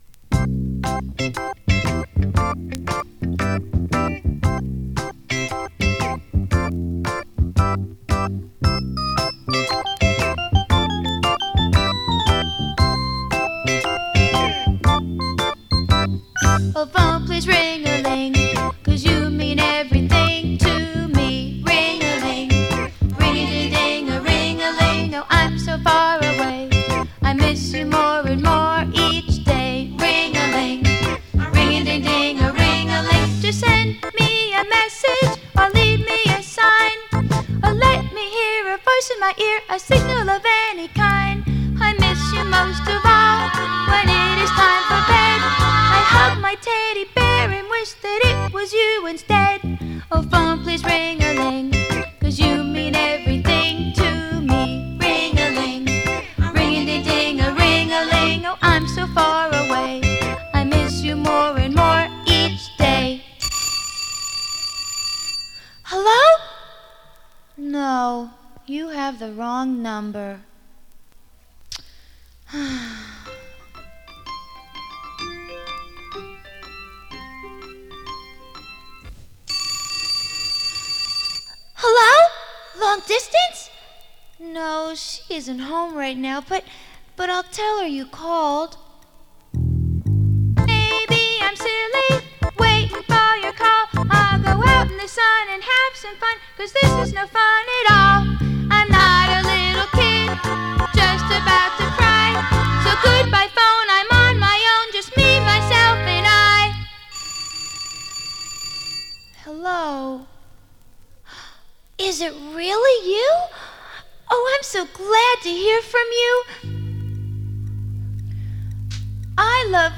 It was also pressed on the shittiest vinyl they could find.